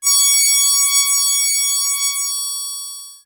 Machine28.wav